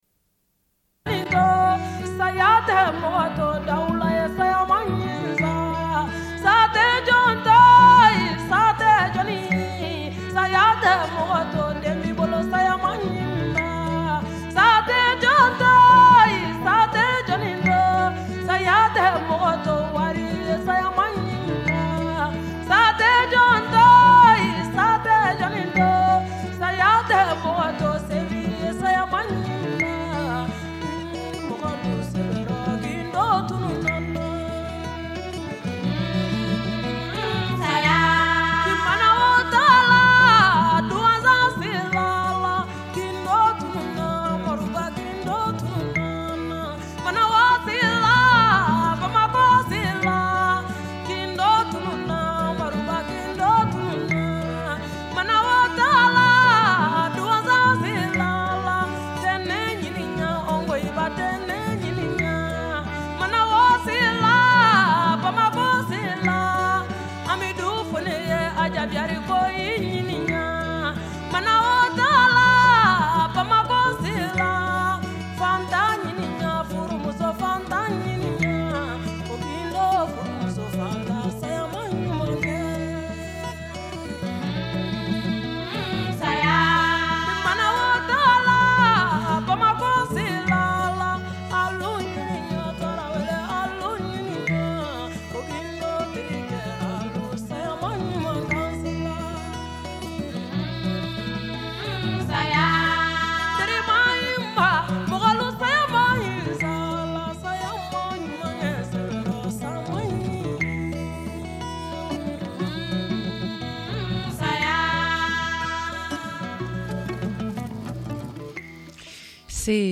Une cassette audio, face B29:05